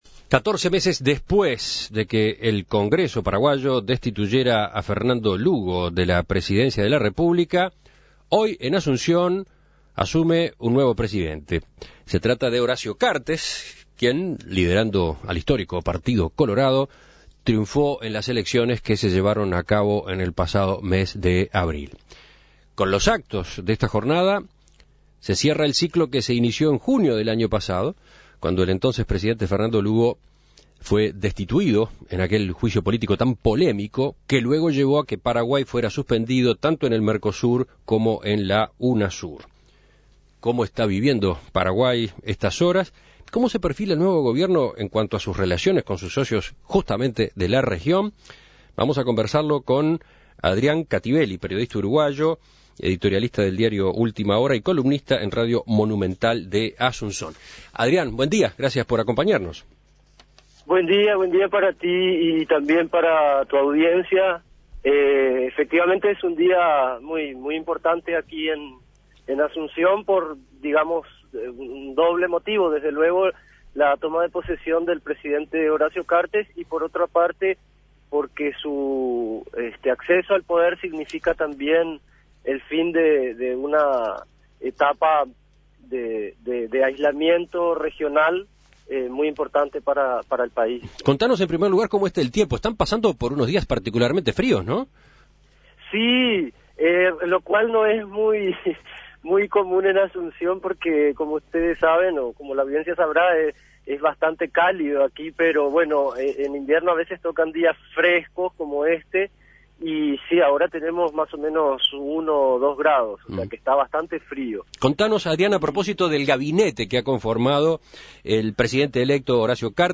periodista uruguayo radicado en Paraguay.